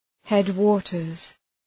Προφορά
{‘hed,wɒtərz}